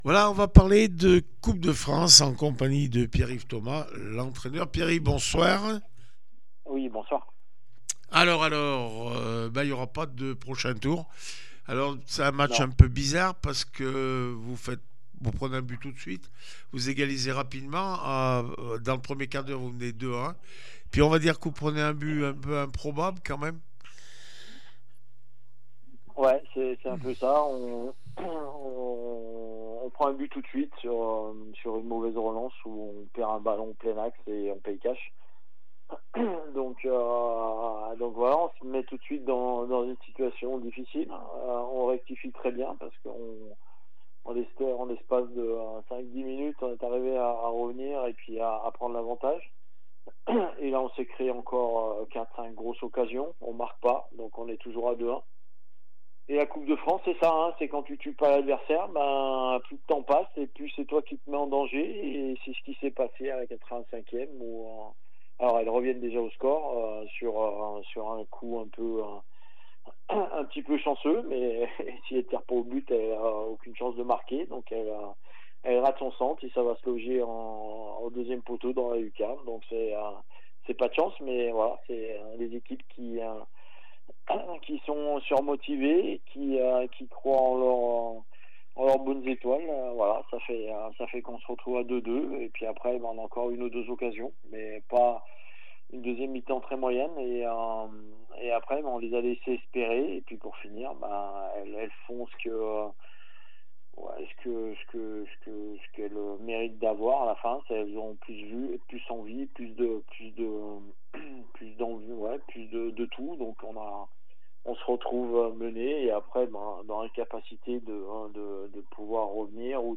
25 novembre 2025   1 - Sport, 1 - Vos interviews
coupe de France féminine Nîmes 3-2 le puy foot 43 réaction après match